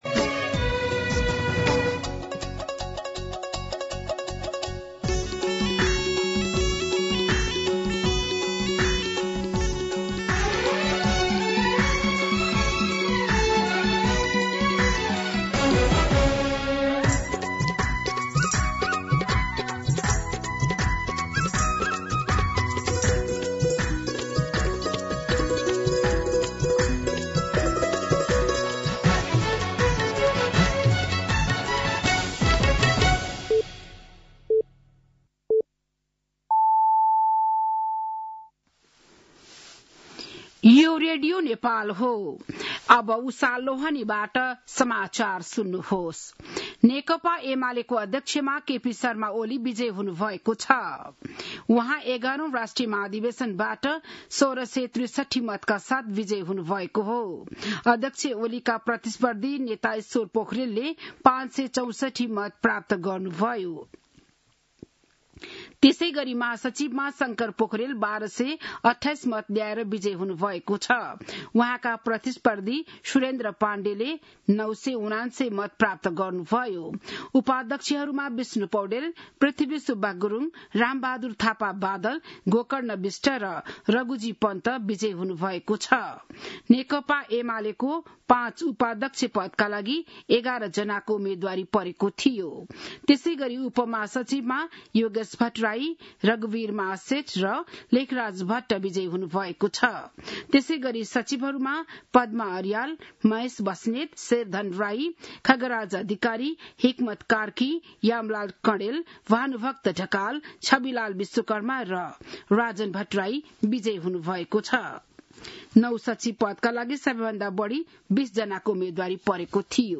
बिहान ११ बजेको नेपाली समाचार : ४ पुष , २०८२